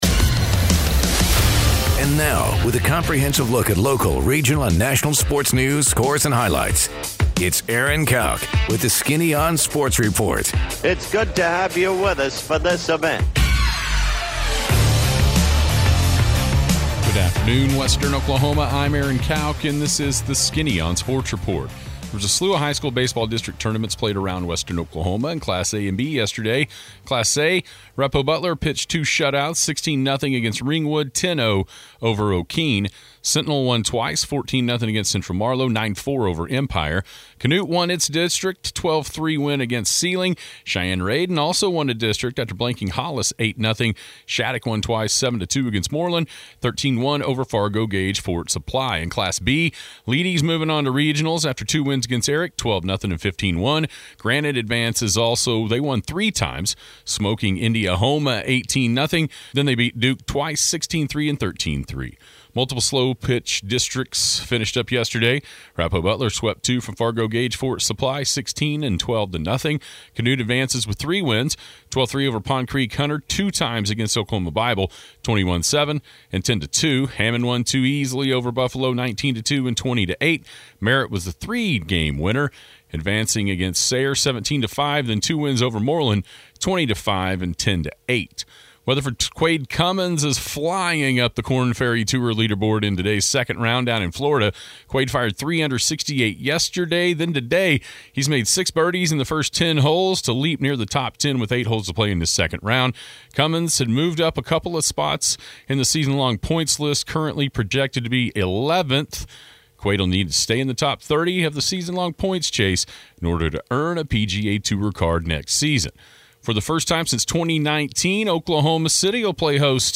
Sports Report